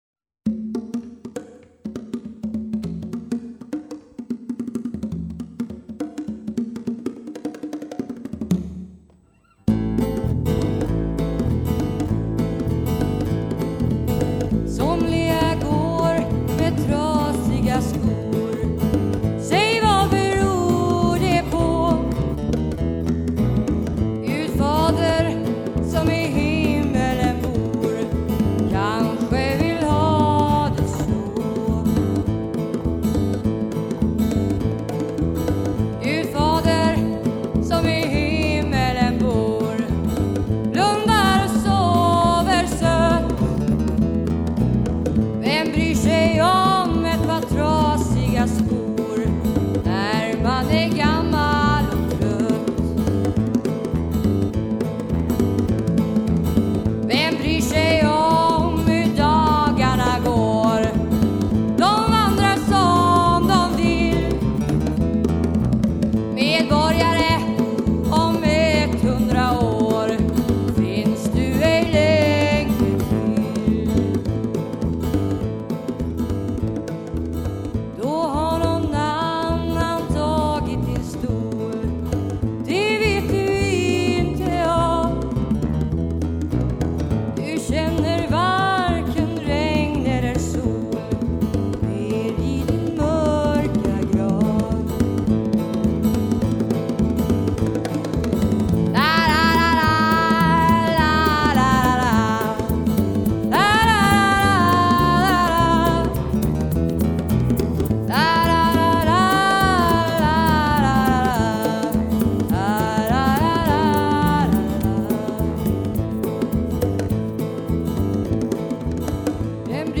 sång